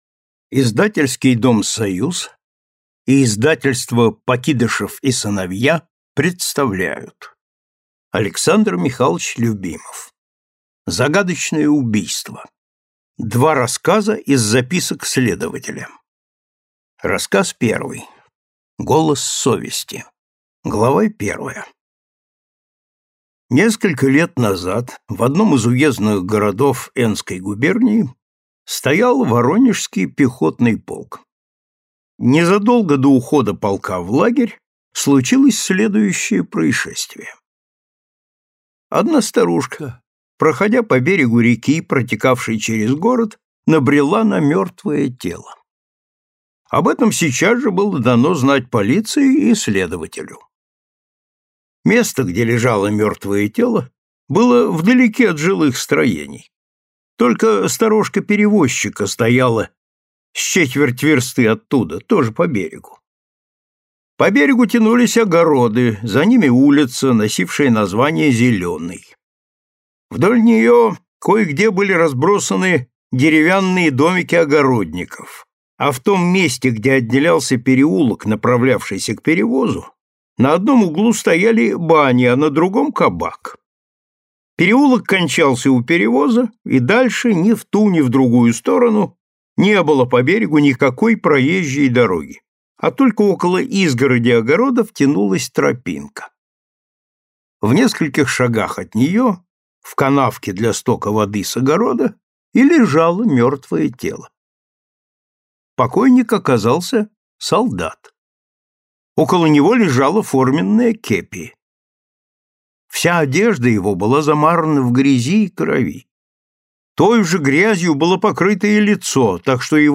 Аудиокнига Загадочное убийство | Библиотека аудиокниг
Прослушать и бесплатно скачать фрагмент аудиокниги